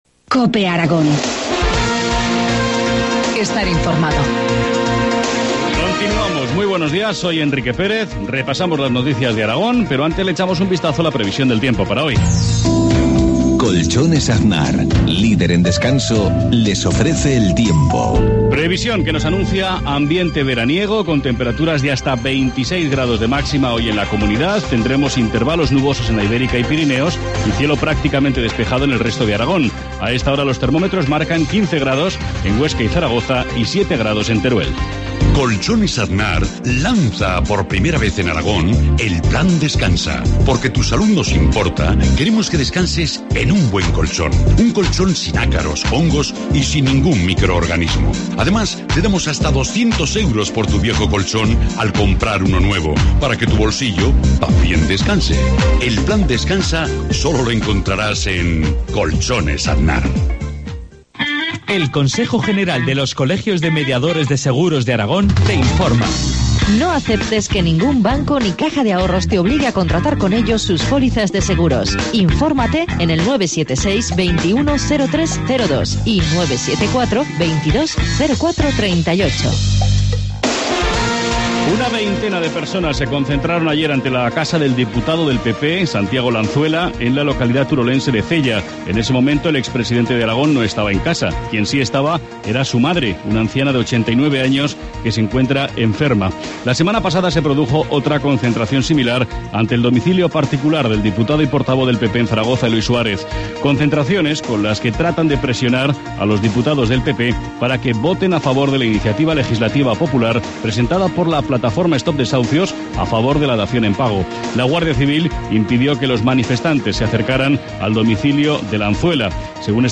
Informativo matinal, lunes 15 de abril, 7.53 horas